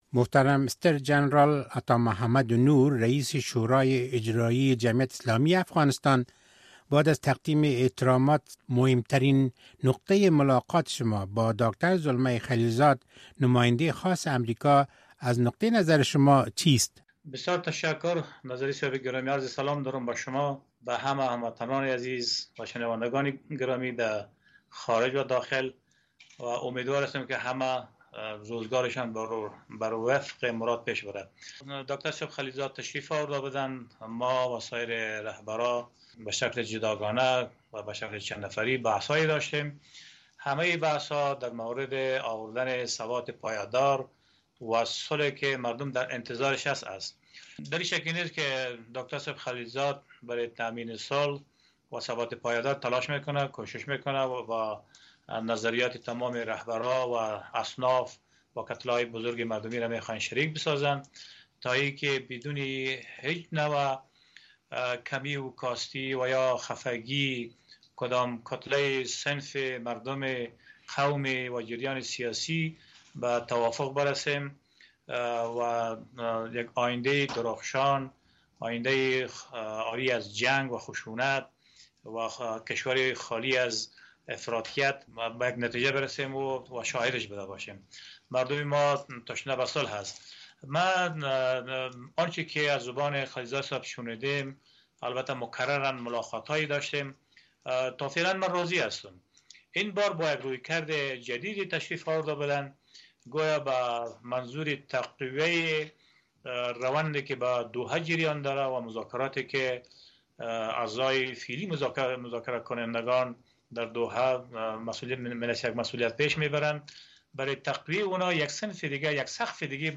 مصاحبه اختصاصی